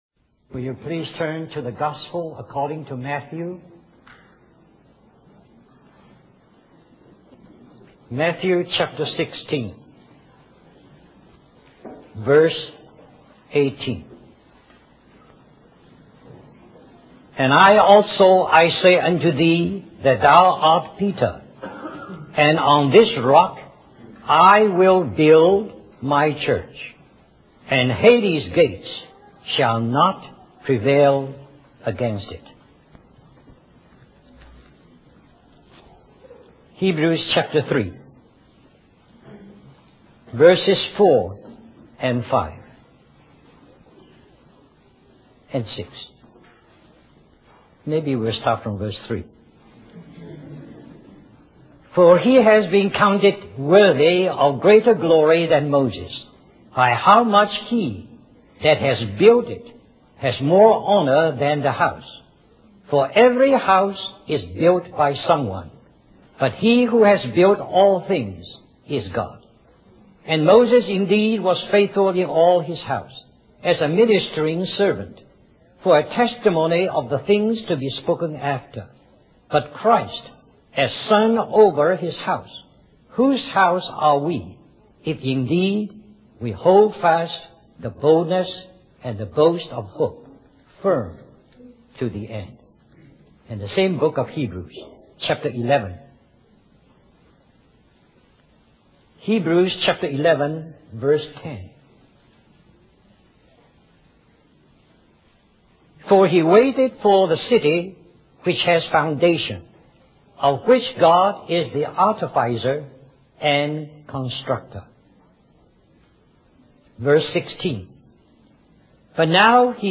A collection of Christ focused messages published by the Christian Testimony Ministry in Richmond, VA.
1992 Harvey Cedars Conference Stream or download mp3 Summary This message is also printed in booklet form under the title